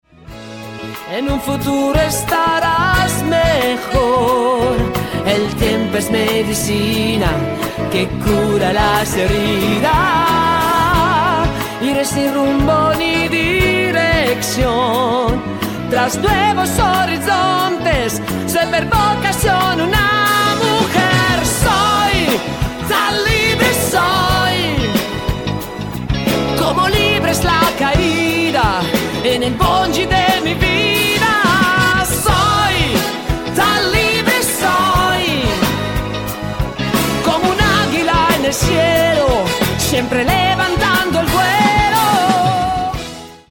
MODERATO  (3.54)